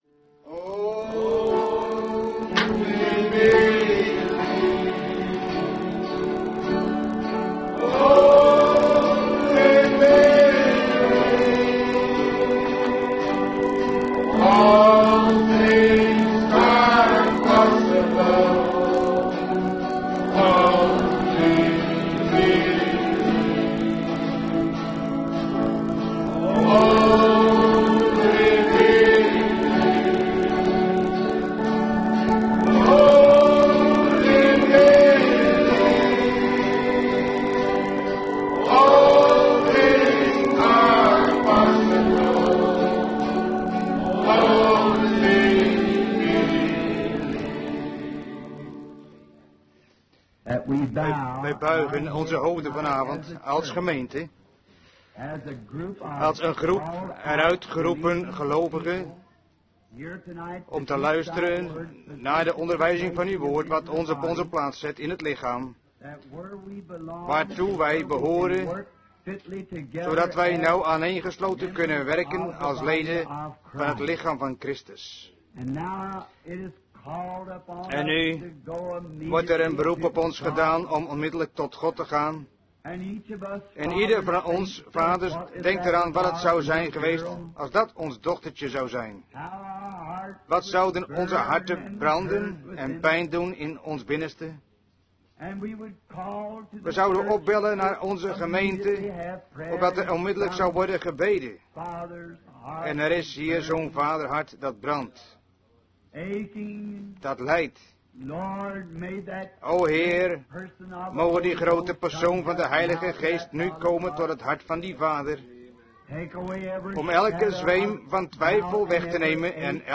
Vertaalde prediking "Manifested sons of God" door W.M. Branham te Branham Tabernacle, Jeffersonville, Indiana, USA, 's avonds op woensdag 18 mei 1960